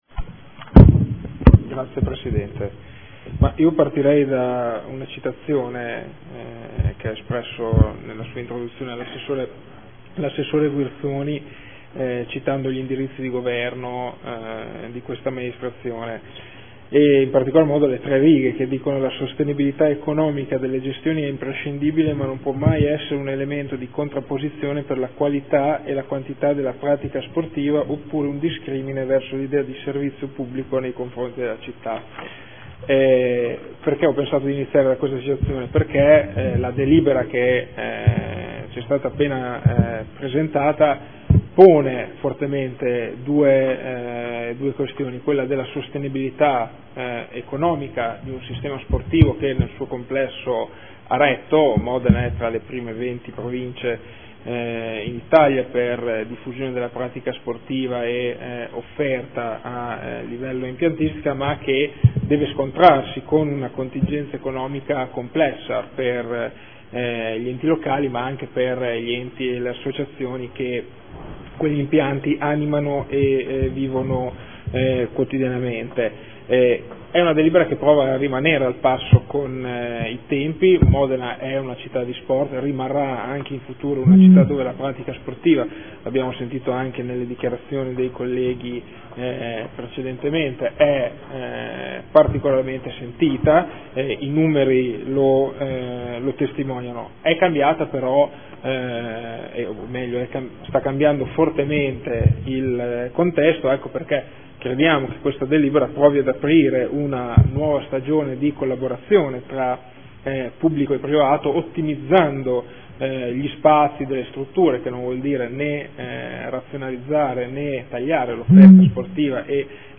Seduta del 09/04/2015 Dichiarazione di voto. Linee di indirizzo per l’affidamento e la gestione degli impianti sportivi di proprietà del Comune di Modena o in sua disponibilità